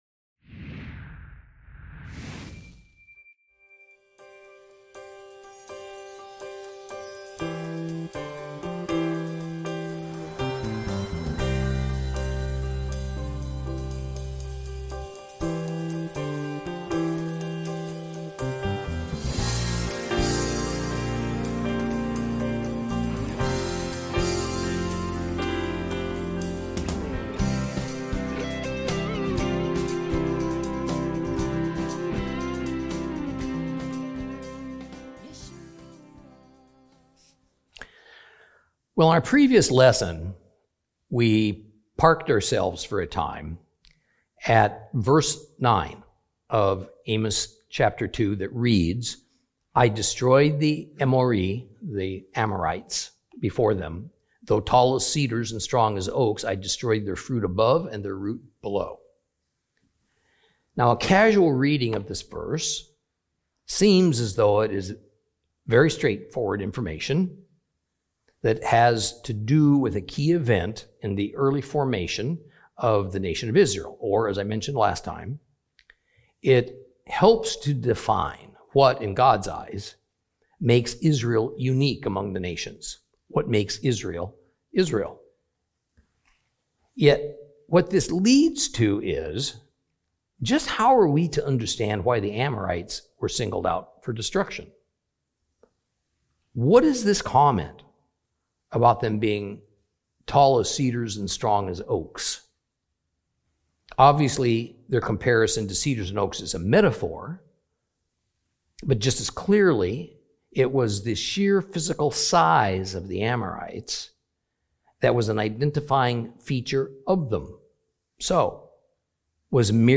Teaching from the book of Amos, Lesson 5 Chapter 2 continued 2.